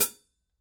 Son à la fermeture: aiguisé, tranchant
Caractère du son: Claire, pleine, chaude, brillante. Spectre large, fréquences équilibrées et concentrées. Sensation de répondant. Clair, sonorité ouverte agressive. Aiguisée, son fermeture tranchant. Excellent pour un jeu net.
signature_14_sound_edge_hi-hat_chick.mp3